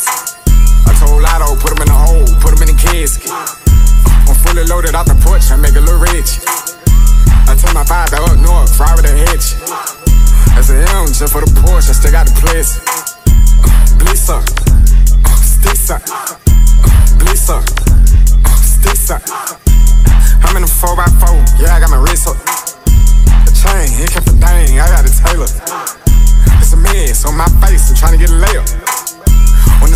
Жанр: Электроника / Африканская музыка
# Afrobeats